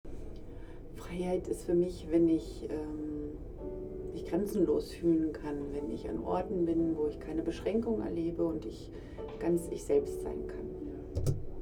FONA Forum @ Berlin, Futurum
Standort war das Berlin, Futurum. Der Anlass war FONA Forum